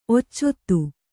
♪ occottu